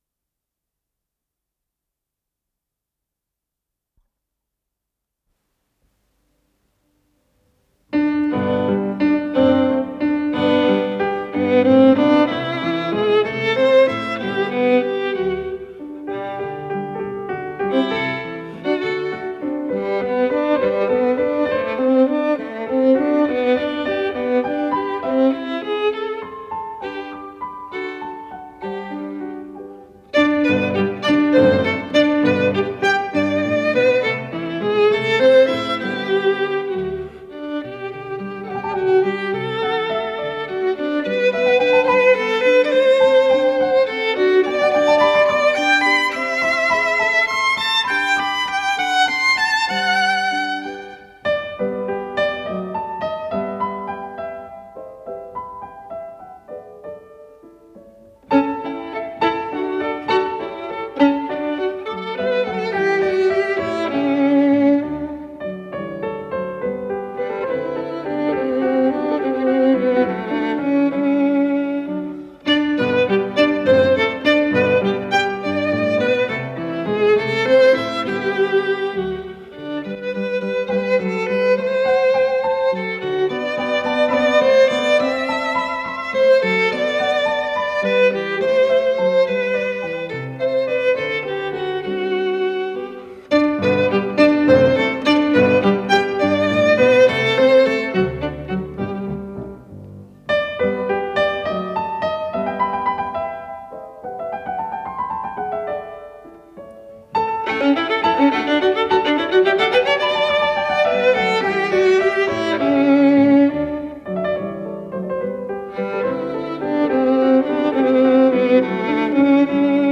Анданте
скрипка
фортепиано
Для скрипки и фортепиано, соль мажор (оригинал - для фортепианного трио)